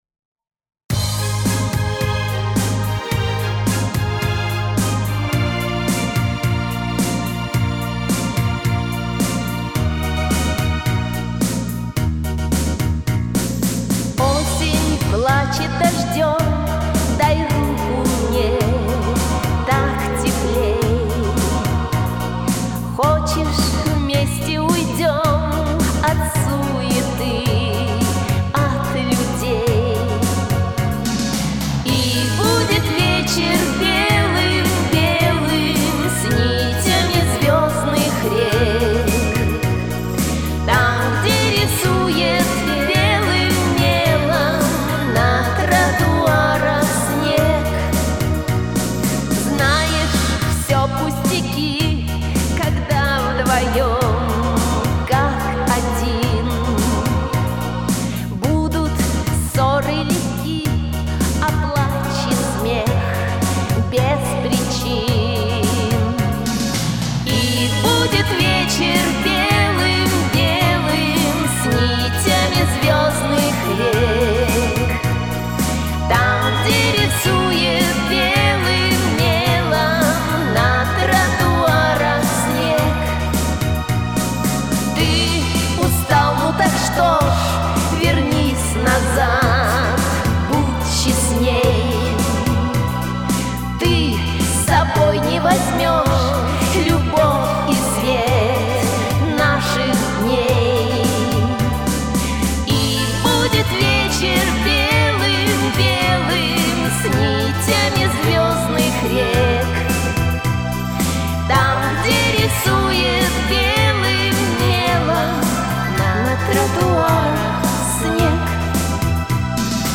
эмоционально нежное многоголосие